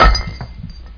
Snap Sound Effect Download
Snap Sound Effect
snap.mp3